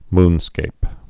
(mnskāp)